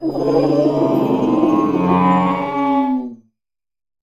Cri de Balbalèze dans Pokémon Écarlate et Violet.